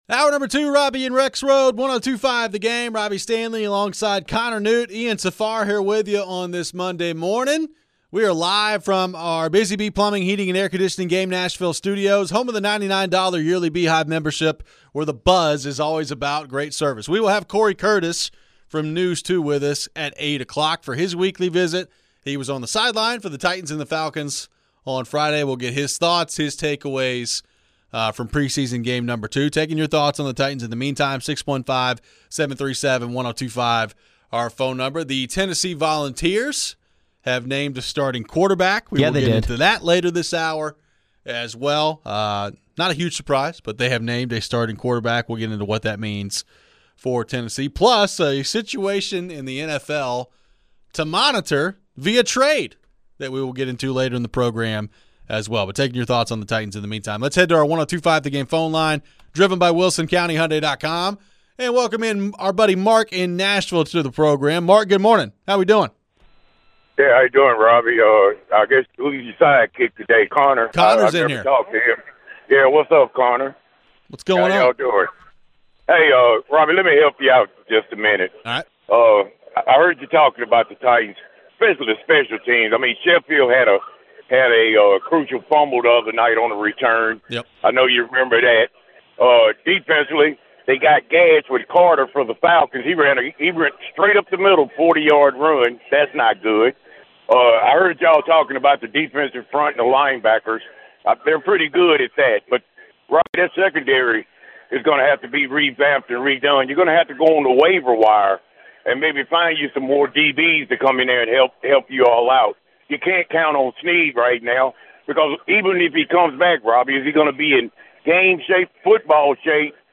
We continue the Titans preseason talk to start this hour and head to the phones. How do we feel about the depth of the secondary and can the team expect an impact from Sneed this season?